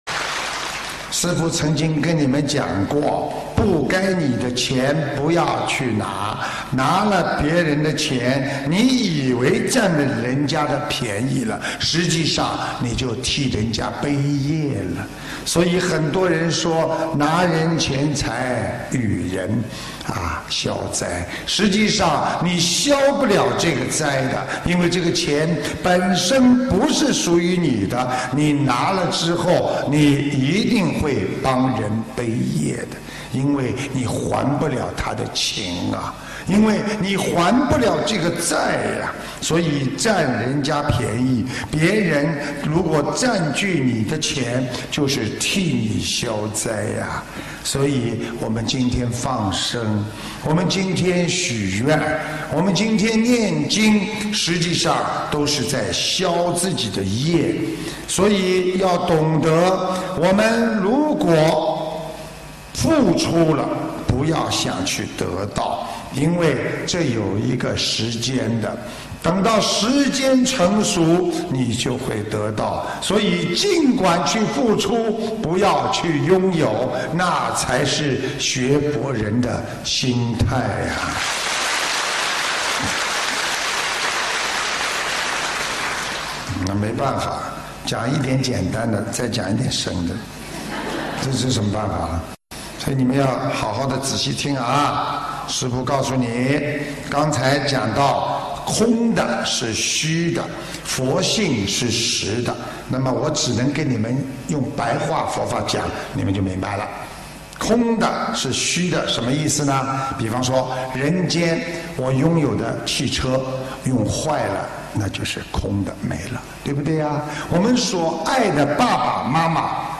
马来西亚槟城